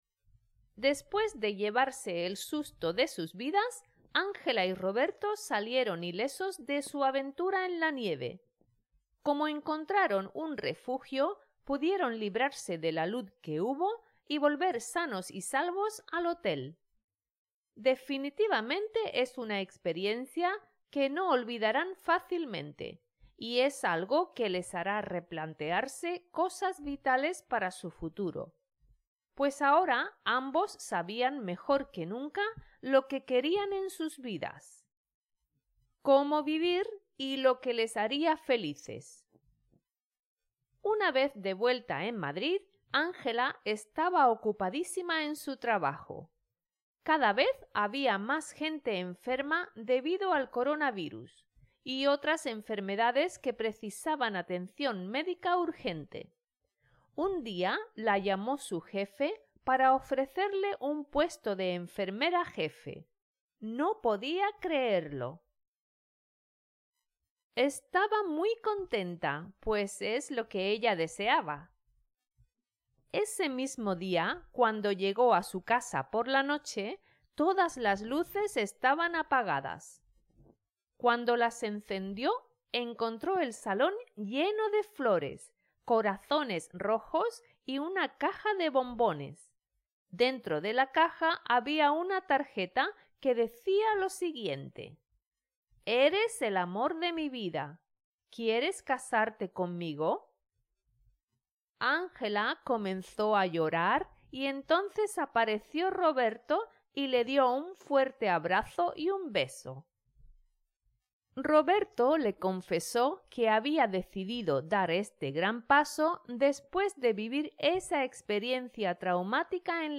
Spanish online reading and listening practice – level B1